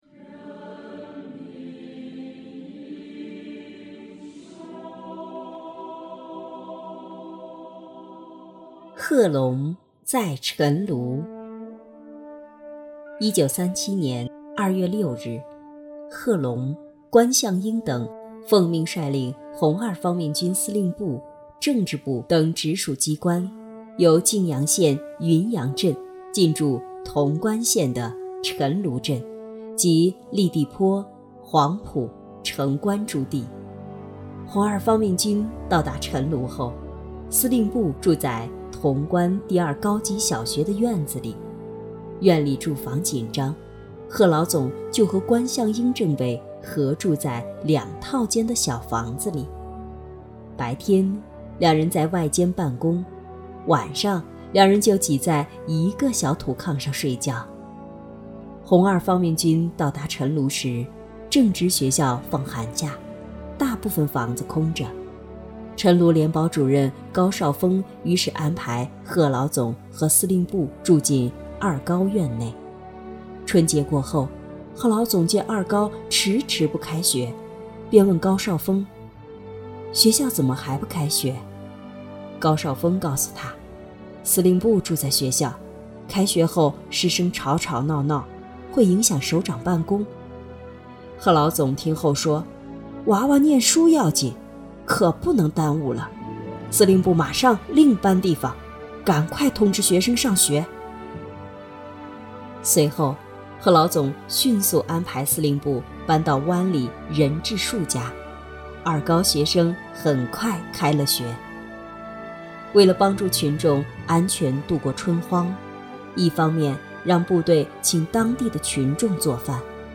【红色档案诵读展播】贺龙在陈炉